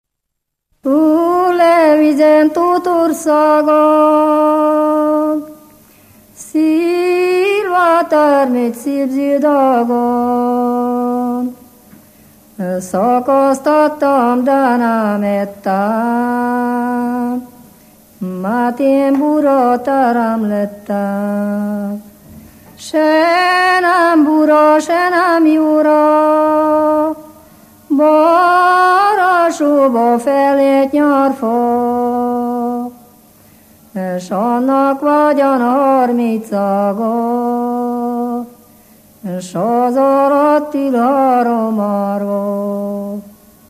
Moldva és Bukovina - Moldva - Klézse
Műfaj: Ballada
Stílus: 3. Pszalmodizáló stílusú dallamok
Szótagszám: 8.8.8.8
Kadencia: 5 (b3) 1 1